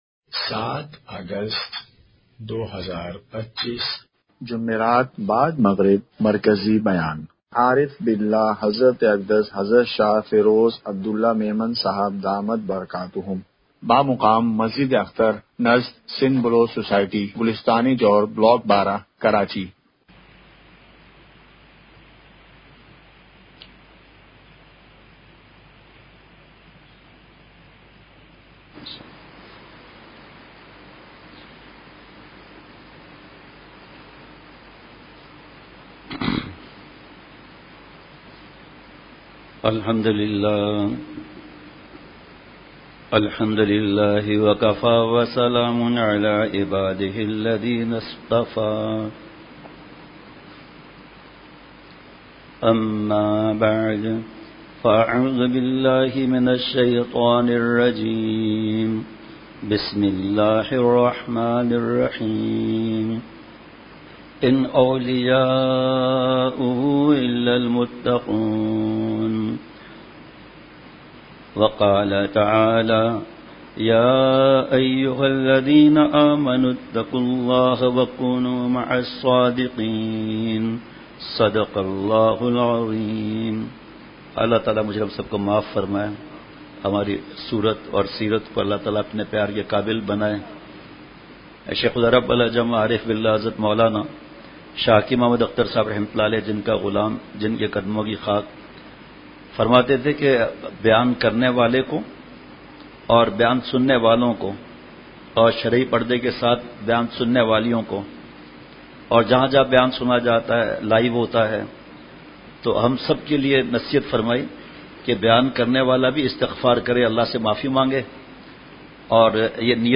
اصلاحی مجلس کی جھلکیاں *مقام:مسجد اختر نزد سندھ بلوچ سوسائٹی گلستانِ جوہر کراچی*